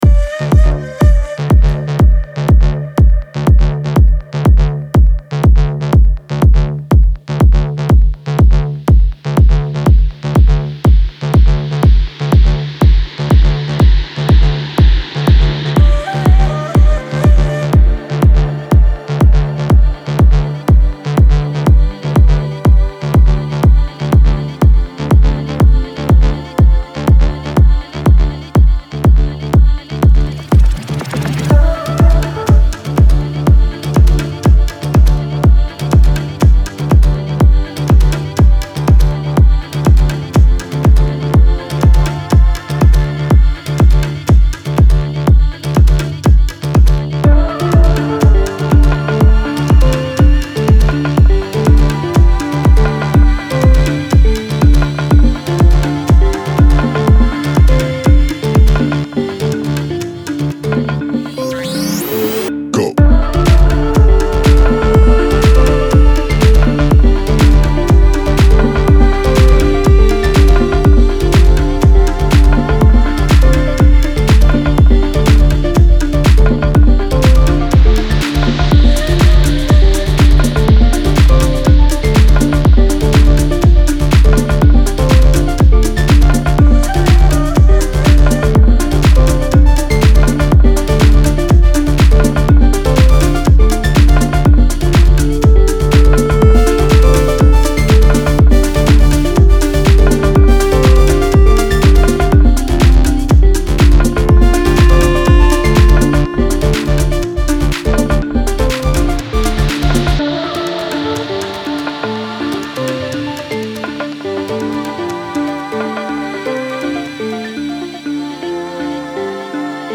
دیپ هاوس
موسیقی بی کلام ریتمیک آرام